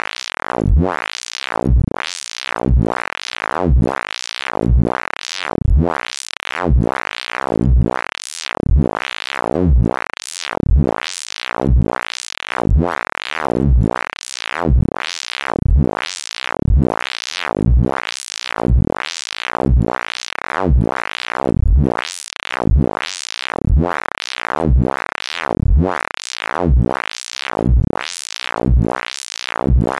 Pulsar synthesis sounds
Sounds like the following can be found in some electronica. In this case it uses a single cycle of a sine wave as the "pulsaret" sound grain which becomes surrounded by silence.